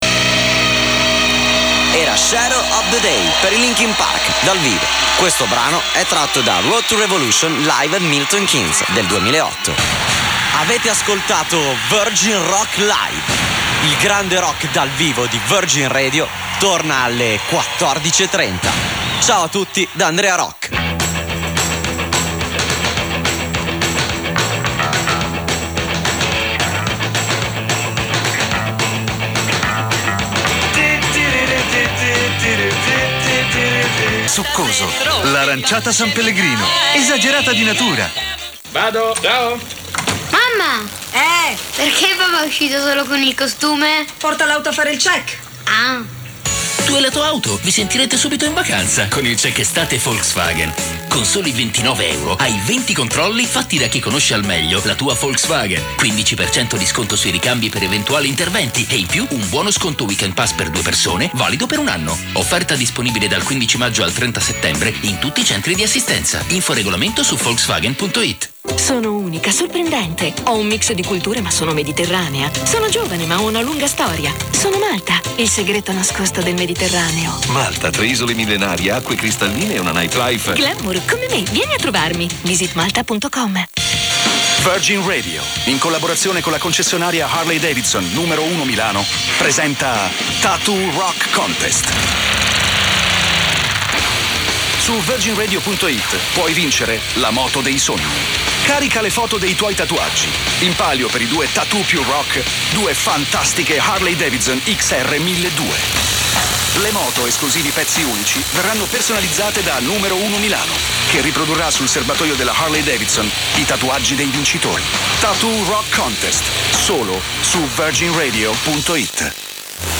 I have never really properly identified any Italian studio links before, but today two were strong.
61.040 approx VIRGIN RADIO with ads in Milano